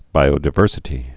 (bīō-dĭ-vûrsĭ-tē)